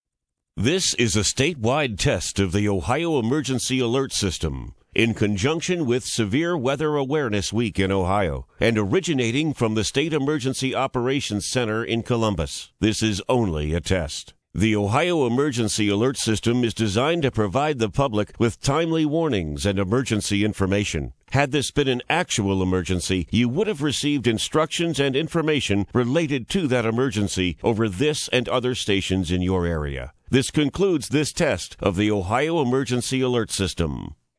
Original IPAWS Audio
EAS Broadcast Content audio/x-ipaws-audio-mp3 · 276061 bytes Embedded